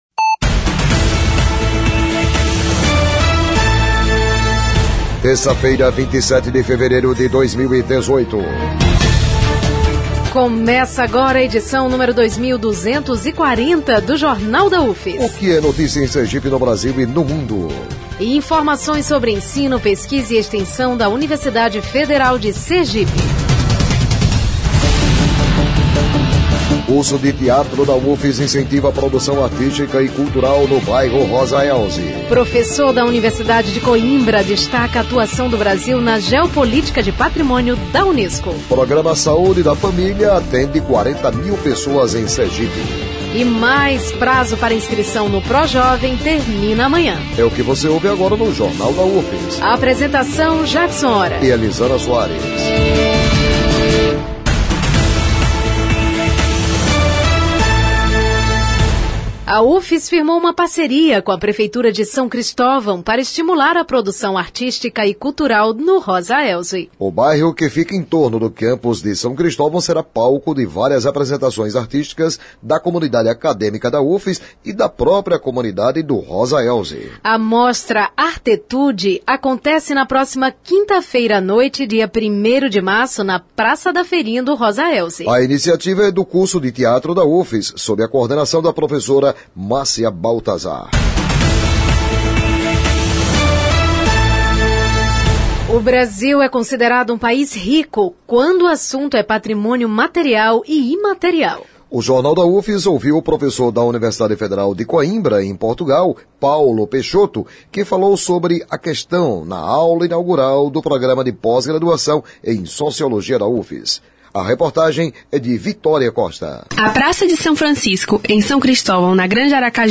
traz uma entrevista
O noticiário vai ao ar às 11h na Rádio UFS, com reprises às 17h e 22h.